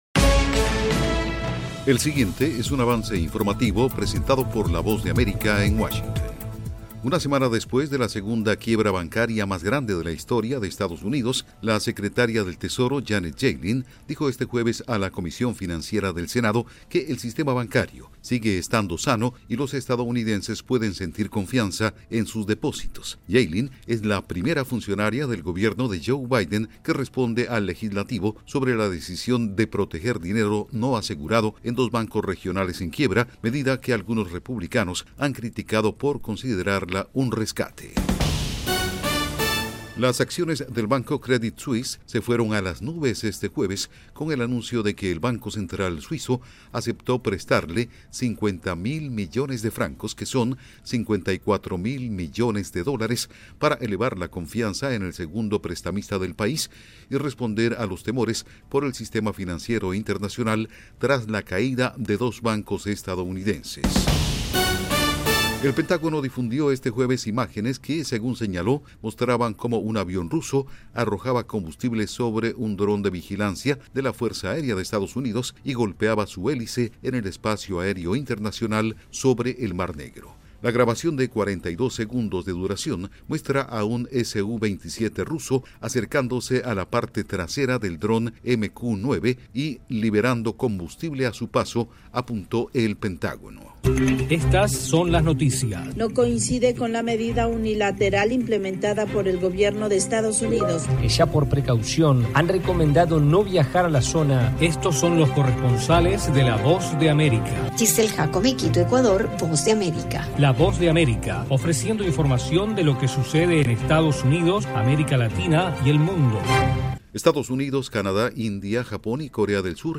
El siguiente es un avance informativo presentado por la Voz de América en Washington